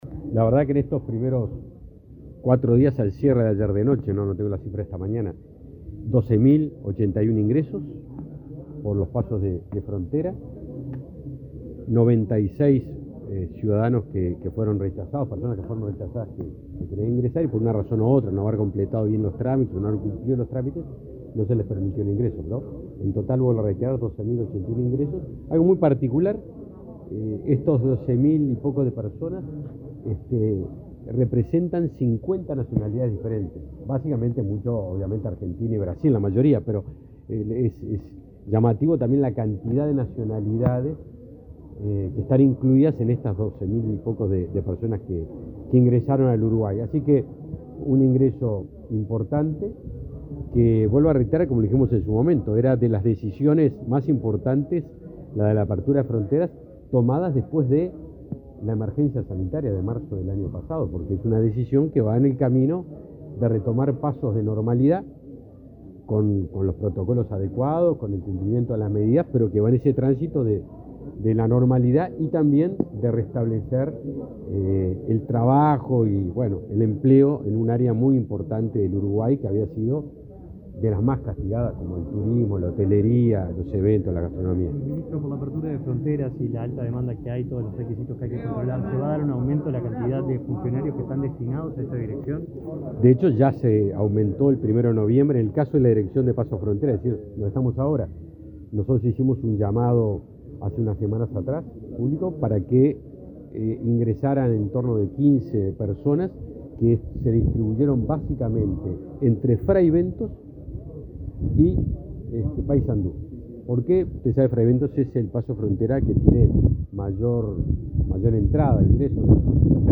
Declaraciones a la prensa del ministro de Defensa, Javier García
El ministro de Defensa, Javier García, participó, este viernes 5 en Montevideo, de la celebración del 45.° aniversario de la Dirección Nacional de